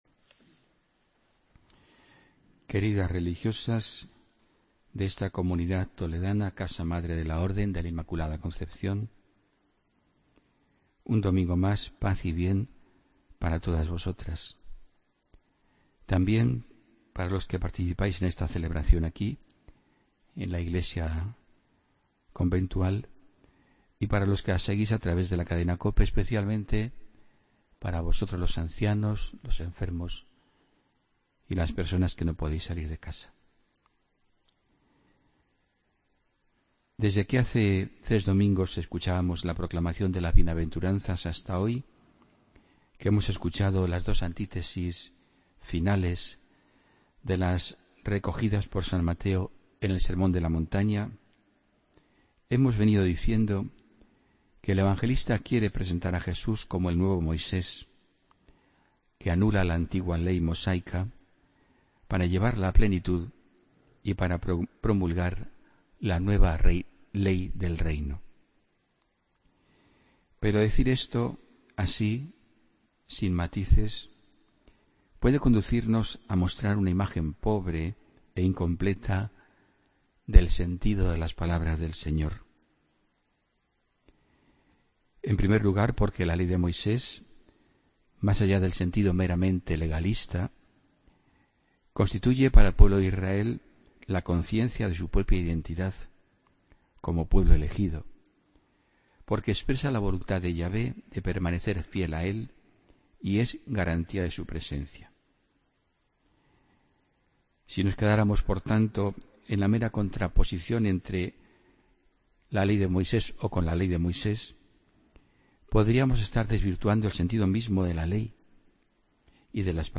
Homilía del domingo 19 de febrero de 2017